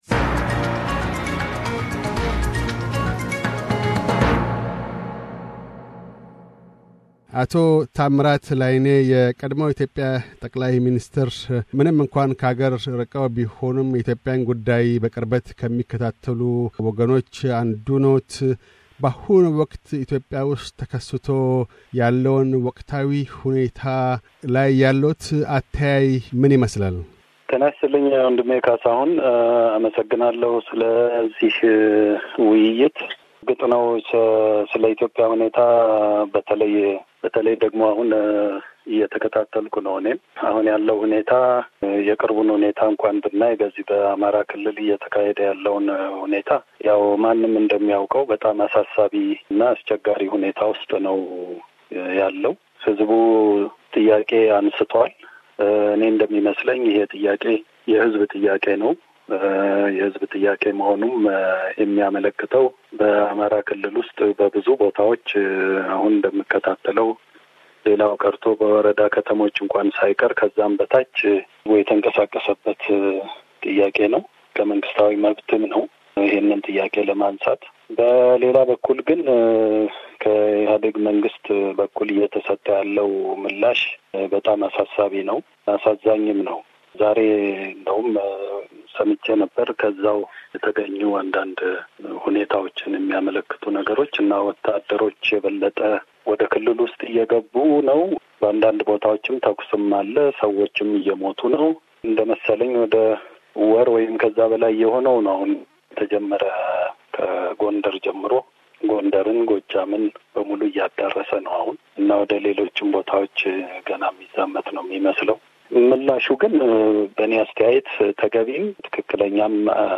የቀድሞው የኢትዮጵያ ጠ/ሚኒስትር ታምራት ላይኔ ስለ በወቅታዊው የኢትዮጵያ ተጨባጭ ሁኔታዎችና ብሄራዊ እርቅ ዙርያ ይናገራሉ።